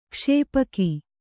♪ kṣēpaki